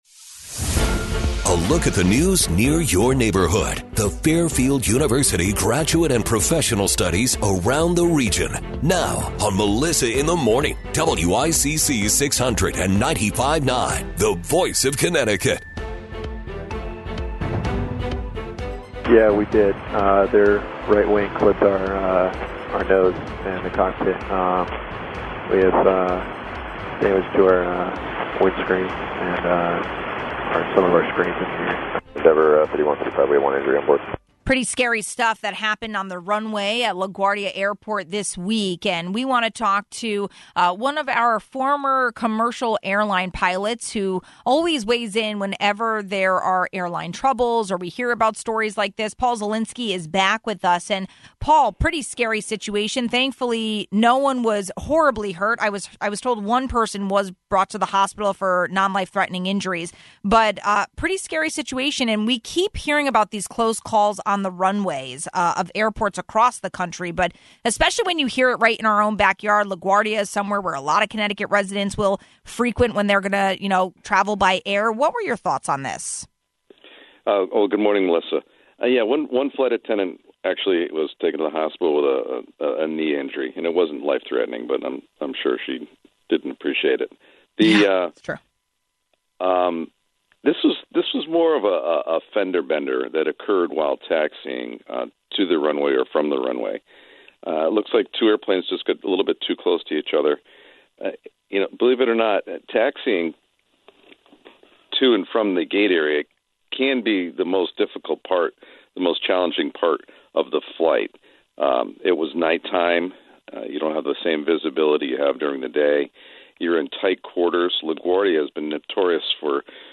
We asked retired commercial airline pilot